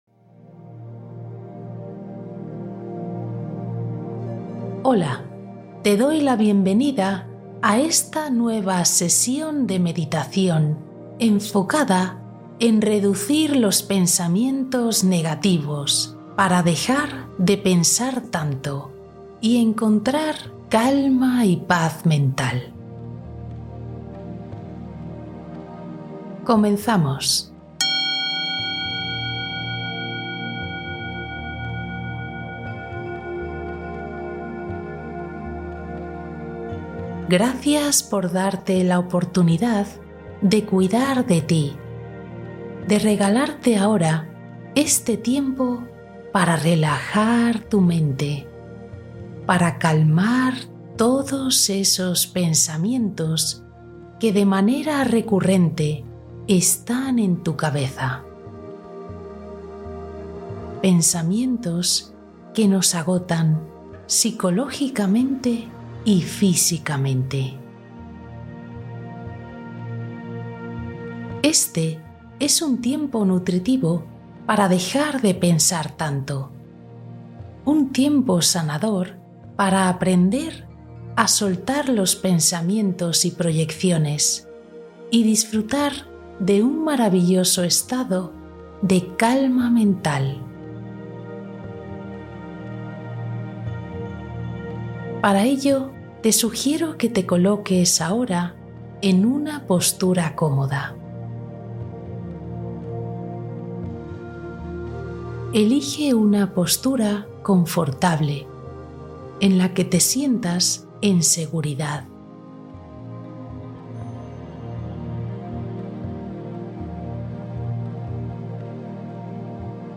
Descansa la mente y libera pensamientos con esta meditación sanadora nocturna